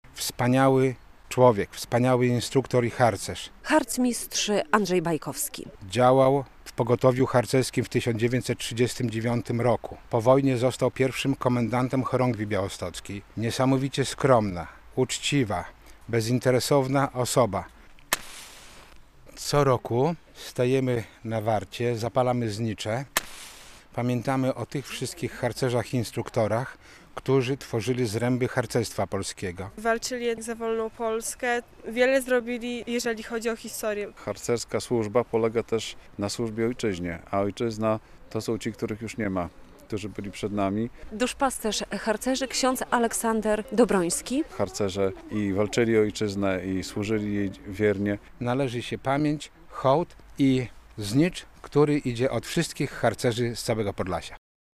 Sprzątali groby i wspominali osoby zasłużone dla ZHP. Harcerze i instruktorzy odwiedzili w sobotę (28.10) miejsca pochówku takich osób na cmentarzu Farnym w Białymstoku.